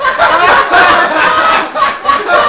laugh1.wav